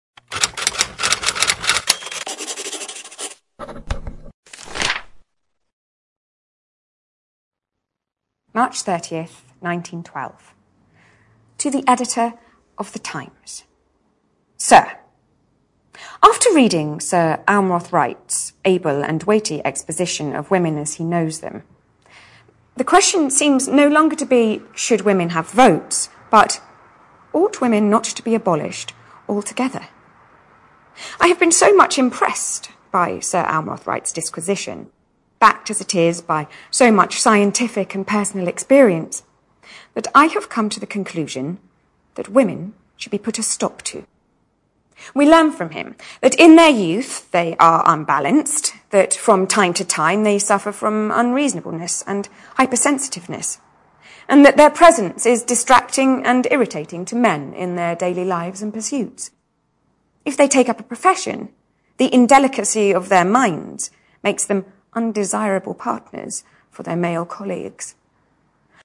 在线英语听力室见信如晤Letters Live 第14期:'露易丝布瑞莉'读信:女性不应该被完全废除吗(1)的听力文件下载,《见信如唔 Letters Live》是英国一档书信朗读节目，旨在向向书信艺术致敬，邀请音乐、影视、文艺界的名人，如卷福、抖森等，现场朗读近一个世纪以来令人难忘的书信。